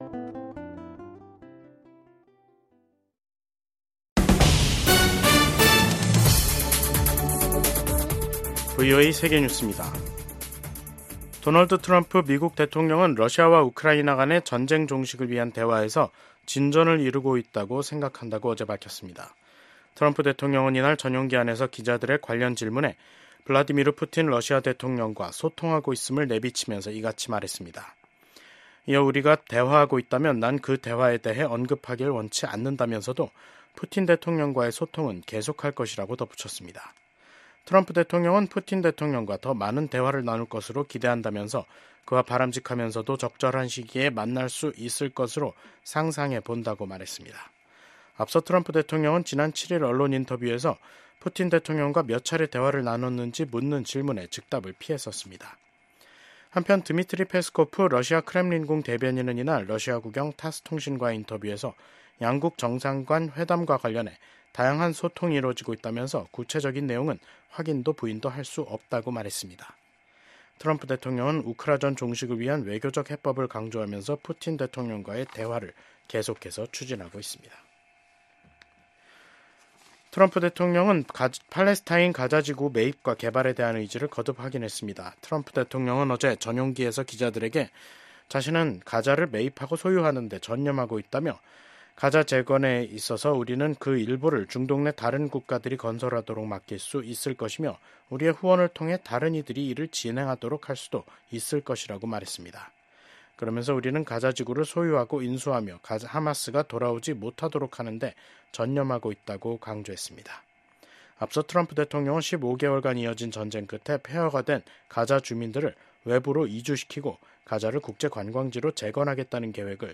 VOA 한국어 간판 뉴스 프로그램 '뉴스 투데이', 2025년 2월 10일 3부 방송입니다. 도널드 트럼프 미국 대통령이 한반도 안정을 위한 노력을 계속하고 김정은 위원장과도 관계를 맺을 것이라고 밝혔습니다. 미국 정부 고위 당국자가 북한의 완전한 비핵화가 트럼프 행정부의 변함없는 목표라는 점을 재확인했습니다. 김정은 북한 국무위원장은 미국이 세계 각지 분쟁의 배후라고 주장하면서 핵 무력 강화 방침을 재확인했습니다.